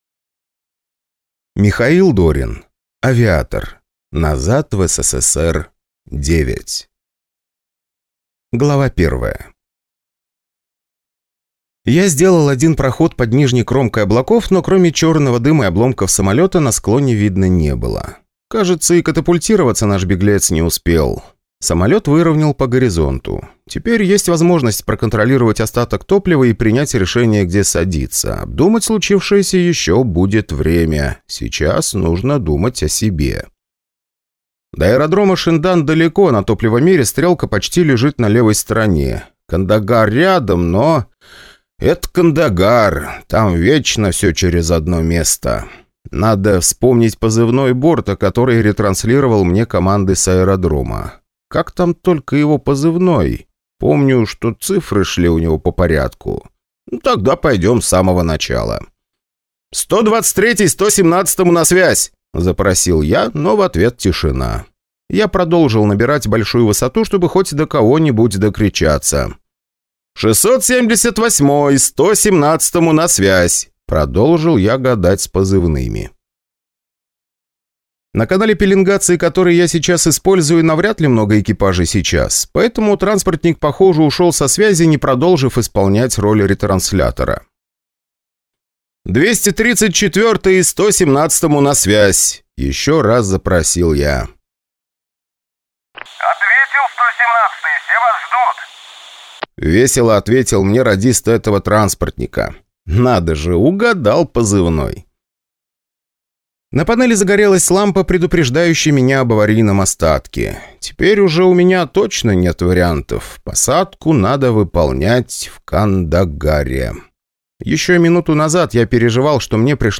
Неожиданный наследник (слушать аудиокнигу бесплатно